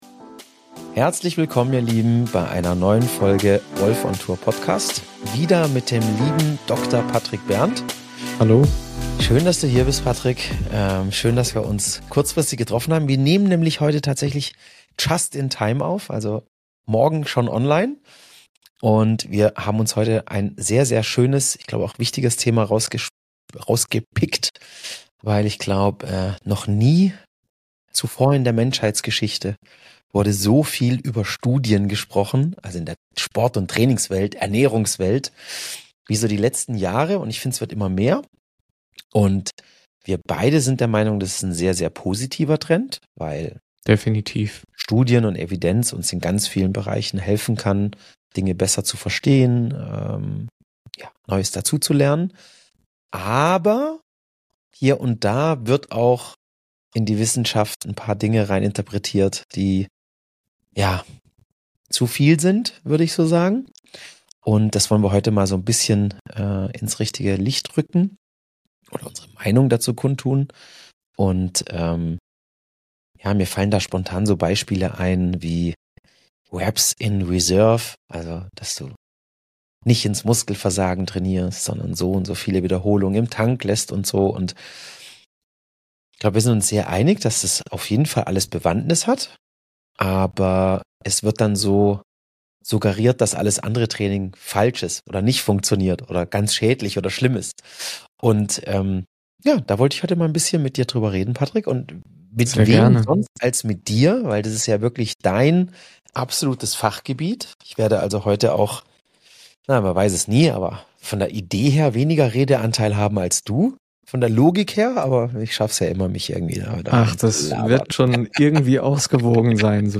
Was kann man wirklich aus wissenschaftlichen Arbeiten ableiten - und wo werden Daten falsch interpretiert? Ein ehrliches Gespräch über Evidenz, Erfahrung und gesunden Menschenverstand.